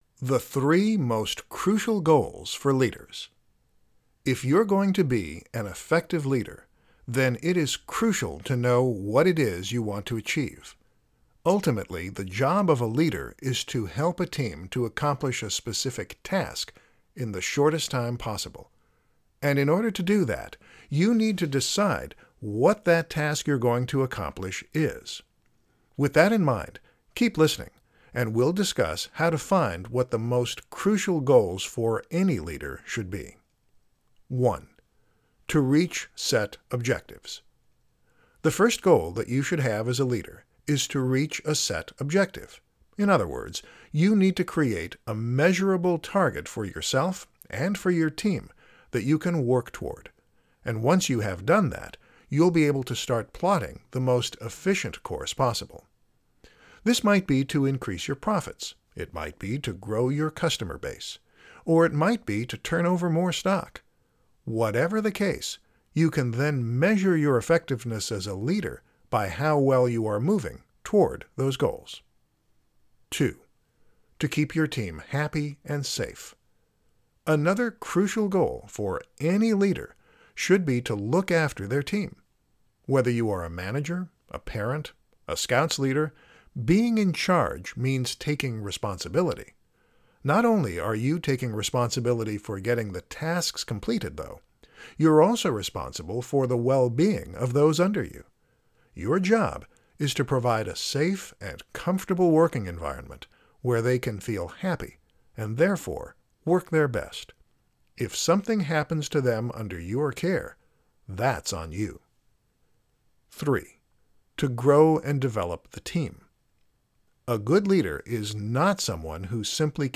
audiobook, ebook and bonus material
Download Audio Book “Leadership-Authority”